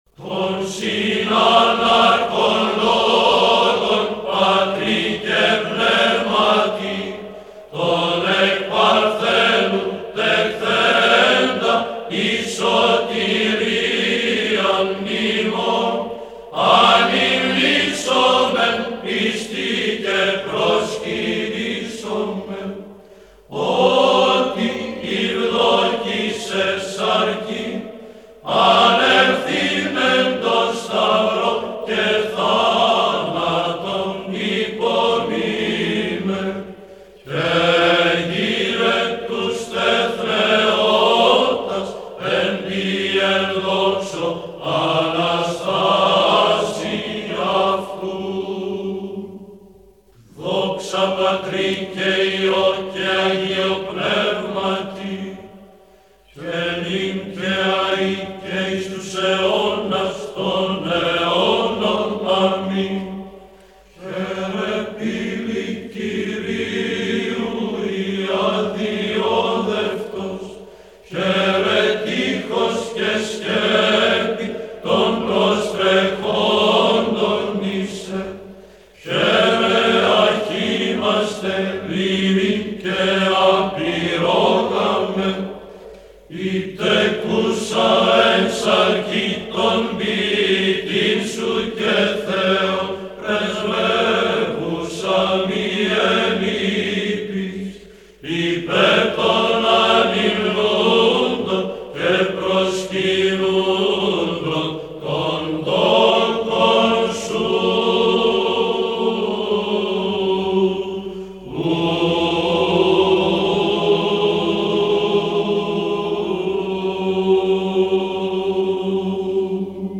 Απολυτίκια Πλαγίου του Πρώτου ήχου – χορός Πανελληνίου συνδέσμου Ιεροψαλτών «Ρωμανός ο Μελωδός και Ιωάννης ο Δαμασκηνός».mp3